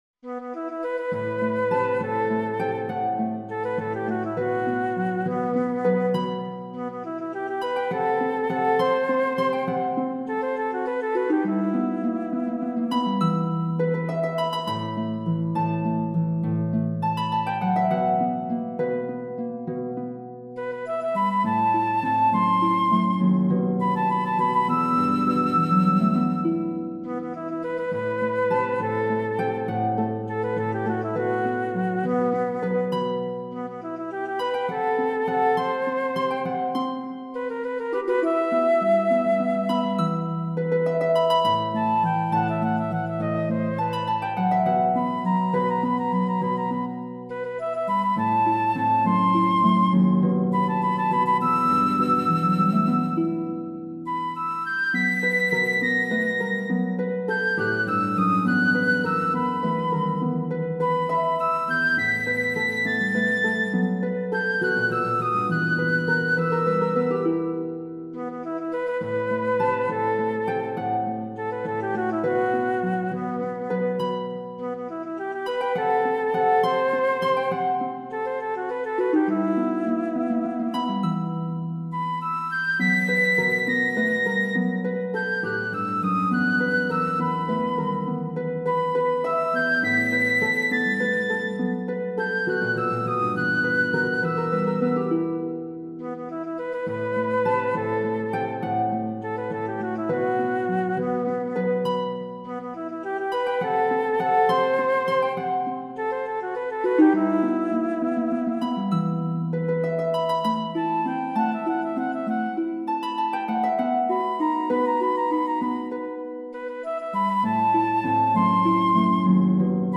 フルートとハープのための小曲集
自由気ままに生きることを好む、風来坊のような感じで。